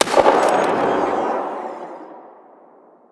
AntiMaterialRifle_far_02.wav